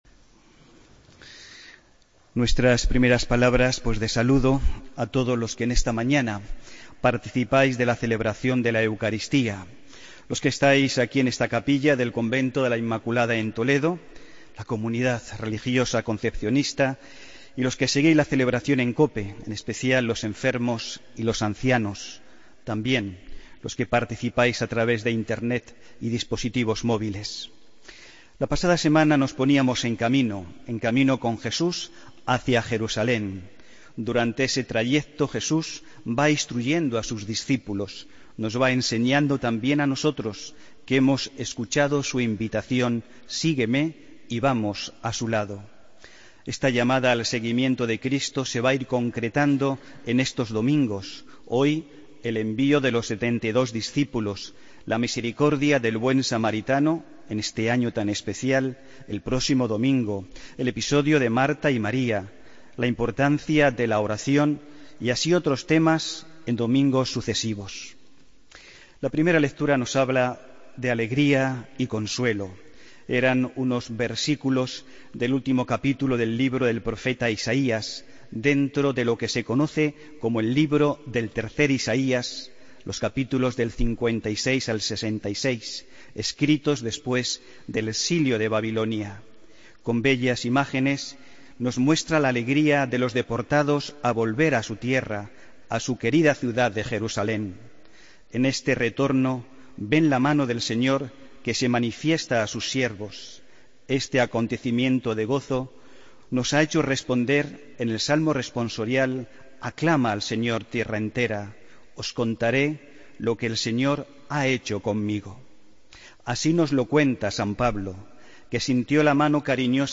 Homilía del domingo 3 de julio de 2016